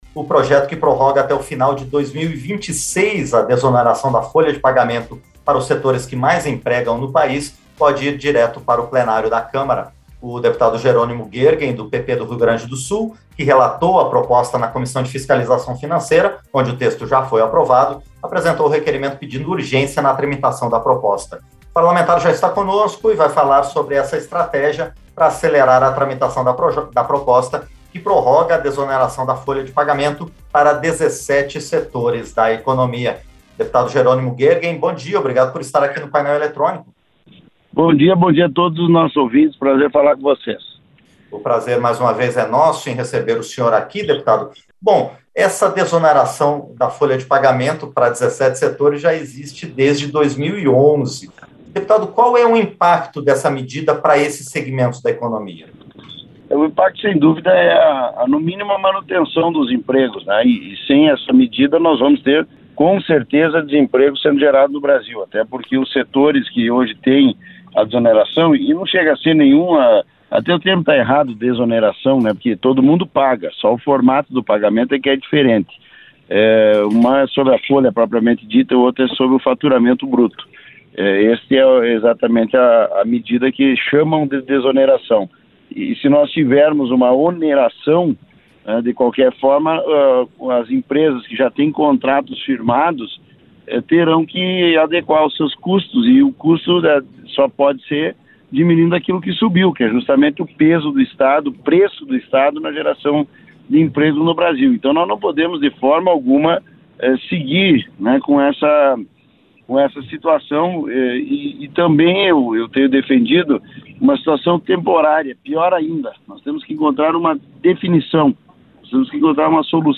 • Entrevista - Dep. Jerônimo Goergen (PP-RS)
Programa ao vivo com reportagens, entrevistas sobre temas relacionados à Câmara dos Deputados, e o que vai ser destaque durante a semana.